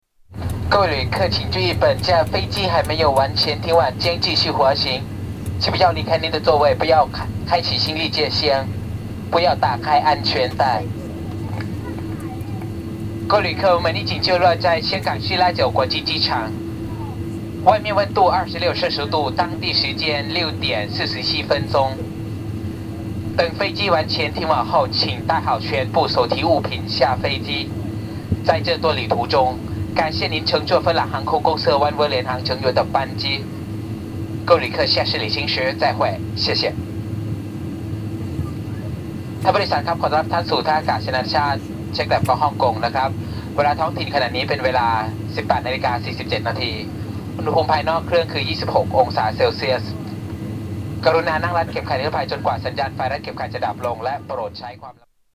Announcements on a finnair flight